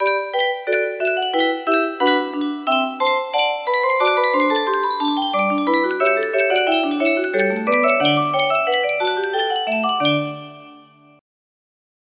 Gavotte by Bach music box melody